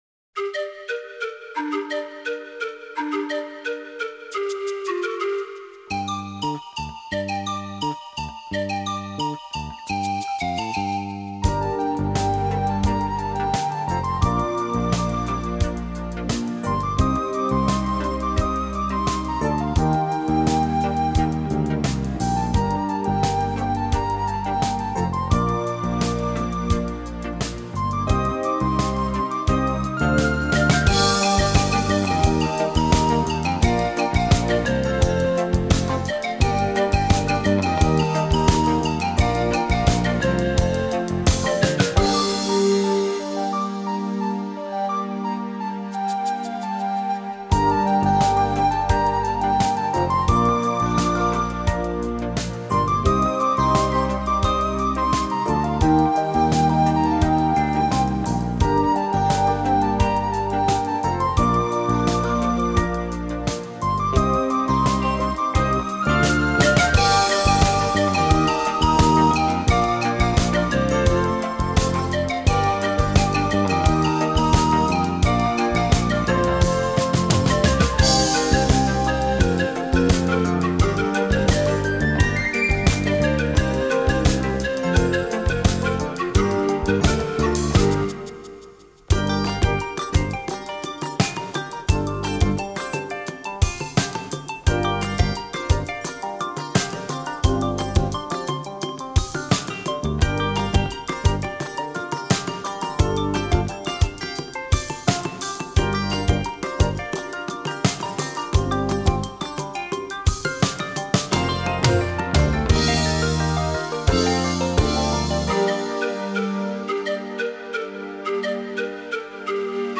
结合印加 民族音乐特色，鲜明而活跃的南美气息，排萧和长笛的运用如流似溪。